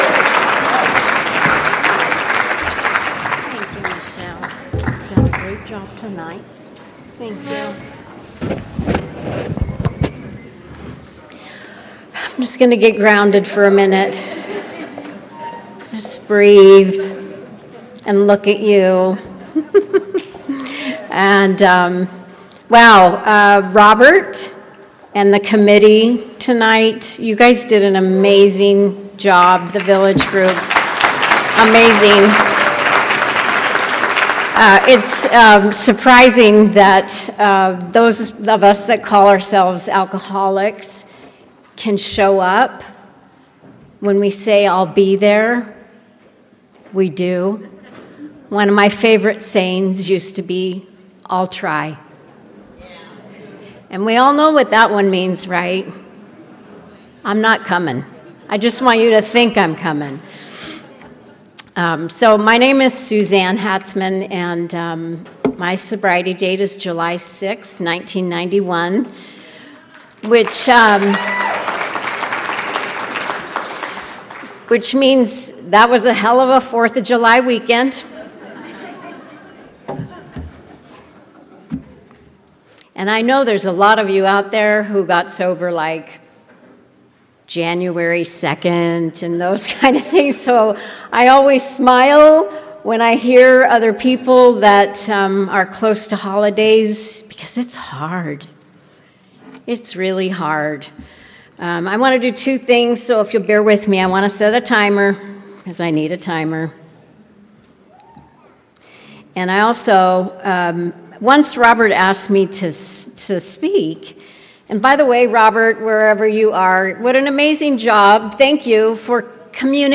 2024 Holiday Soiree - Fresno CA